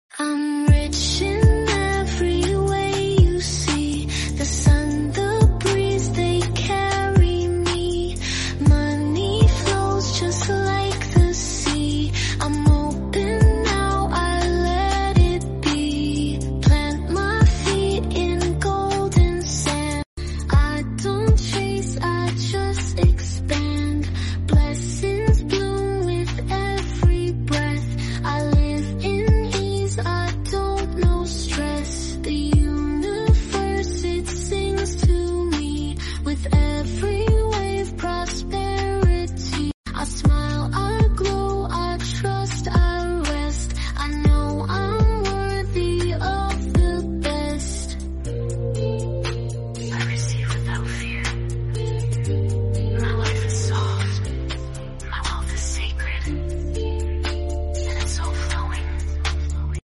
I'm Loving this cool but casual vibe